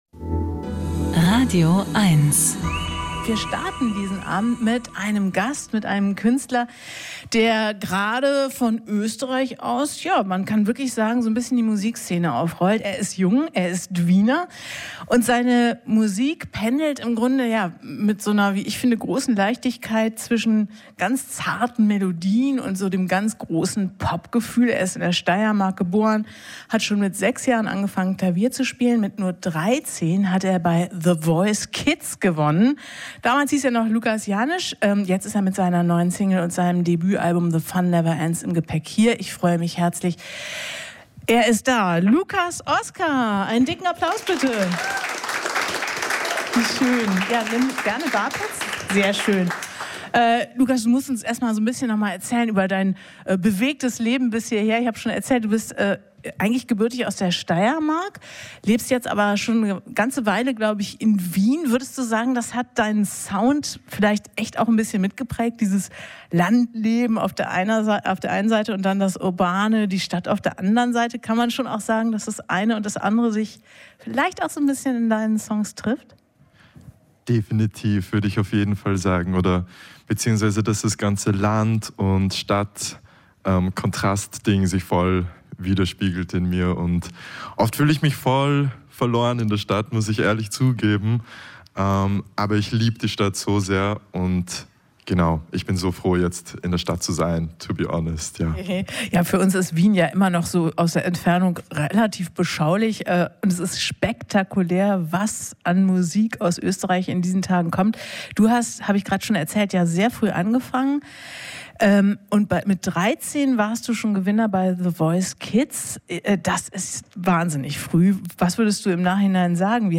Musik-Interviews
Die besten Musikerinnen und Musiker im Studio oder am Telefon gibt es hier als Podcast zum Nachhören.